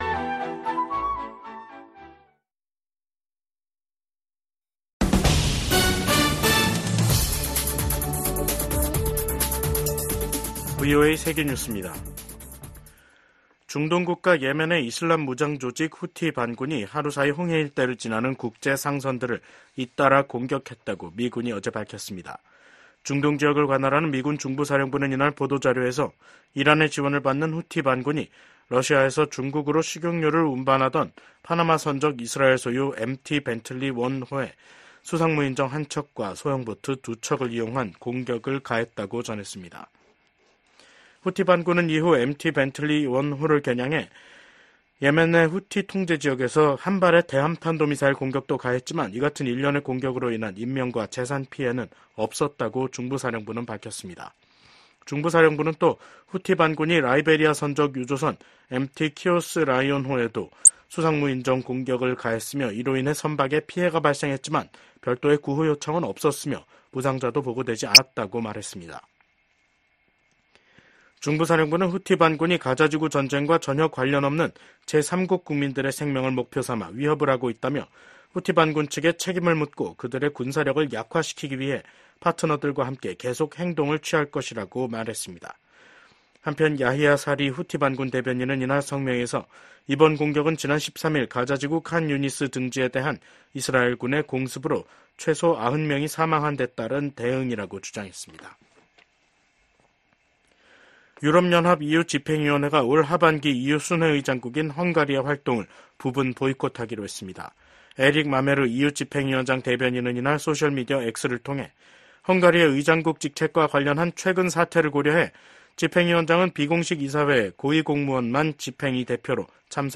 VOA 한국어 간판 뉴스 프로그램 '뉴스 투데이', 2024년 7월 16일 2부 방송입니다. 도널드 트럼프 전 미국 대통령이 공화당 대선 후보로 공식 지명됐습니다.